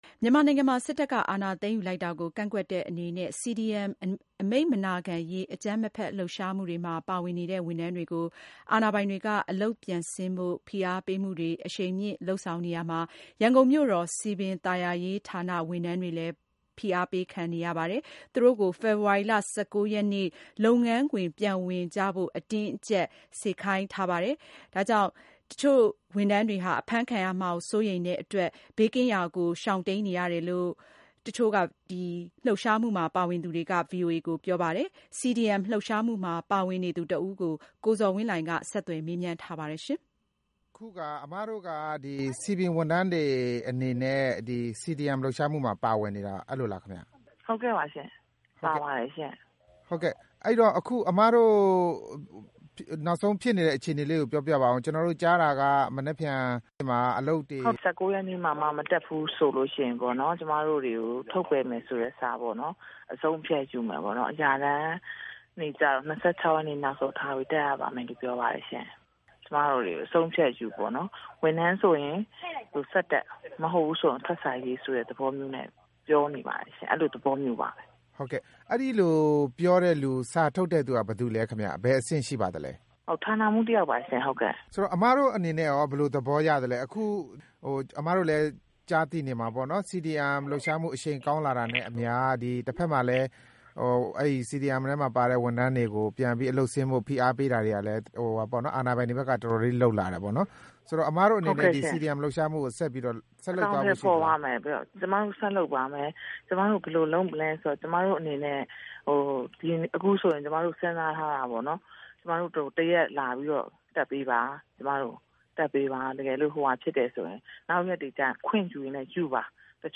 အလုပ်ဆင်းဖို့ ဖိအားပေးခံနေရတဲ့ စည်ပင်ဝန်ထမ်းတဦးနဲ့ ဆက်သွယ်မေးမြန်းချက်